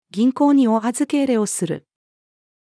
銀行にお預け入れをする。-female.mp3